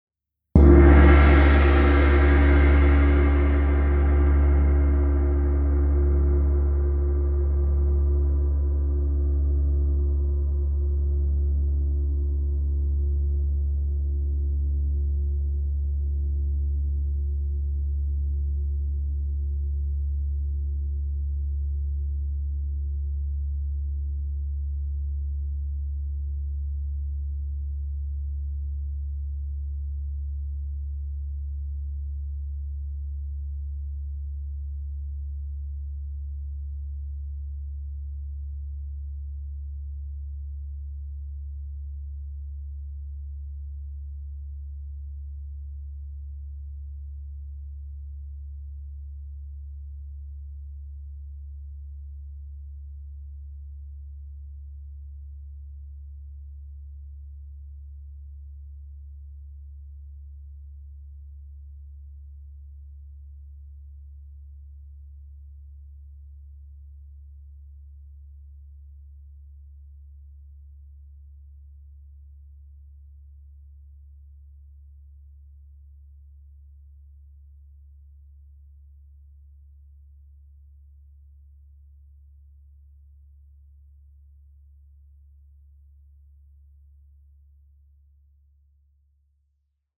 PLAYTECH gong, 38 inches in size.